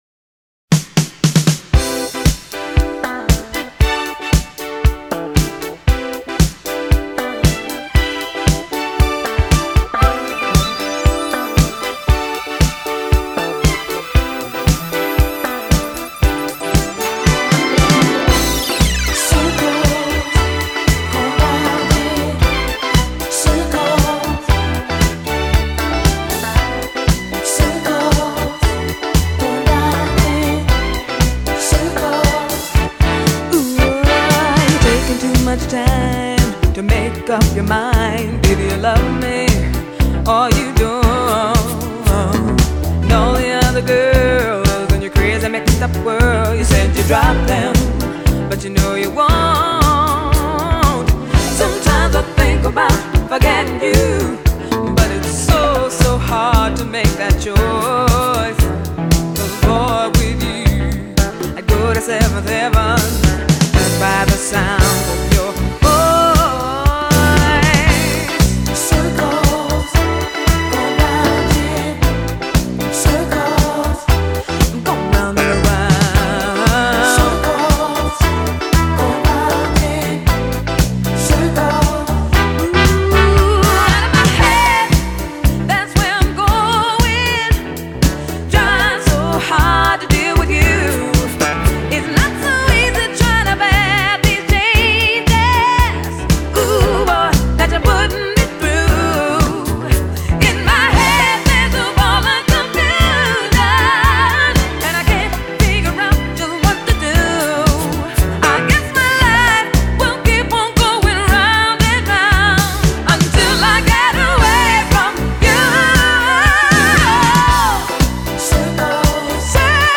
Disco Music Funk
دیسکو فانک